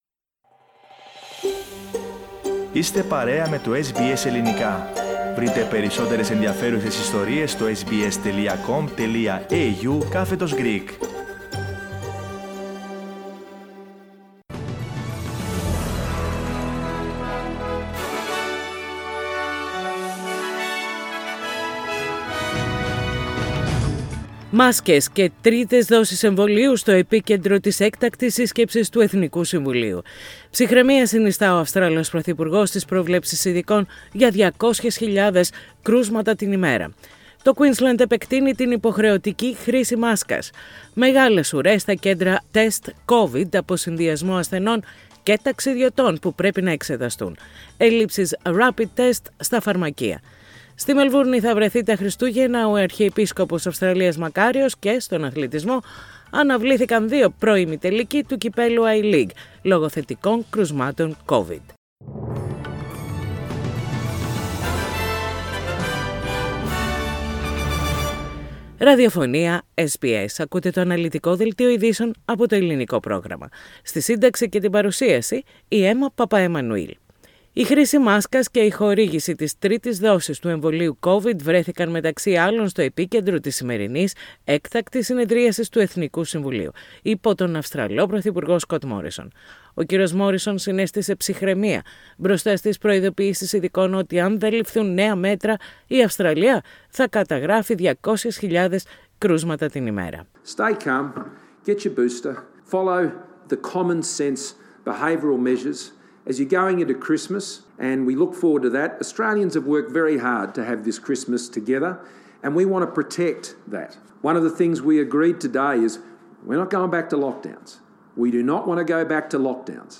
News in Greek - Wednesday 22.12.21
News in Greek. Source: SBS Radio